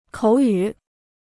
口语 (kǒu yǔ) Dictionnaire chinois gratuit